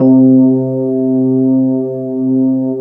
FEND1L  C2-R.wav